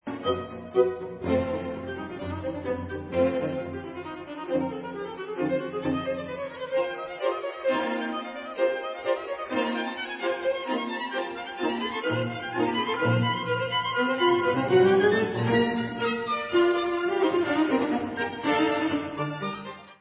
Allegro giocoso (alternativní finálová věta, 1915)